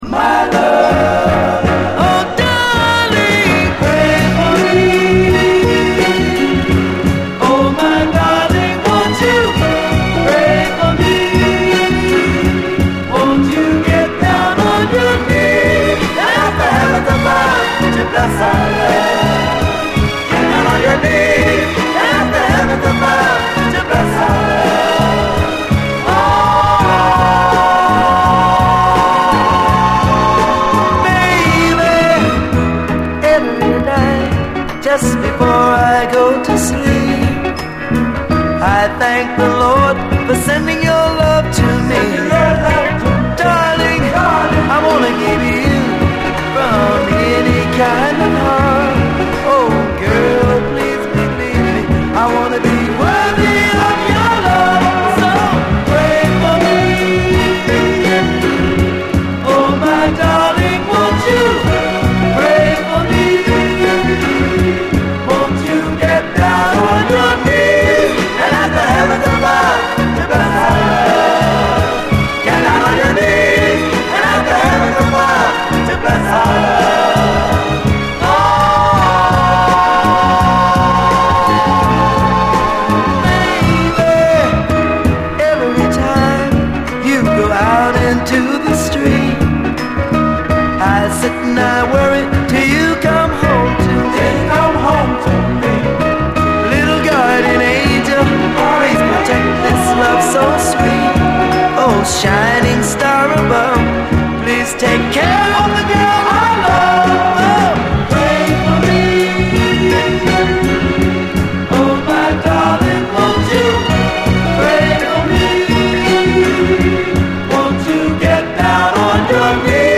SOUL, 70's～ SOUL, 7INCH
７インチ・オンリーの神々しいビューティフル・ソウル45！
ゴスペル・ソウルばりの清らかなコーラス・ワークとメロディーに心が洗われる心地！